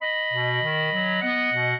minuet8-2.wav